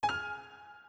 pause-back-click.wav